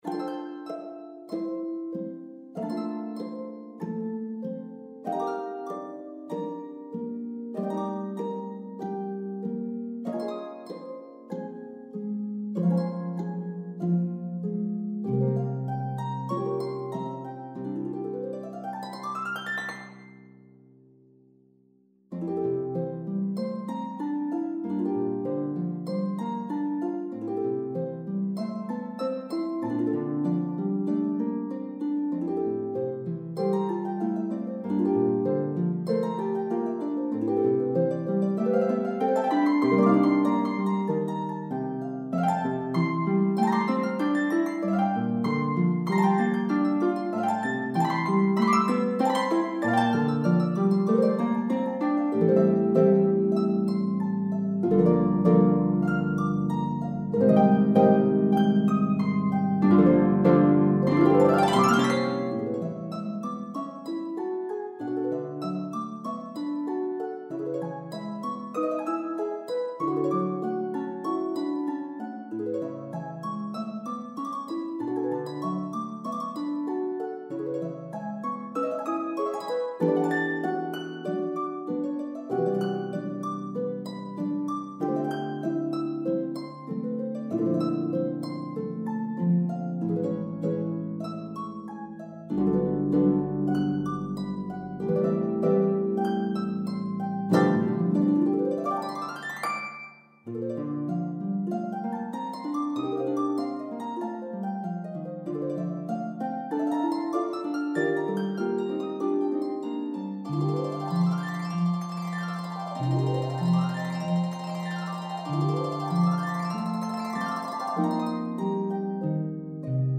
Harp Duo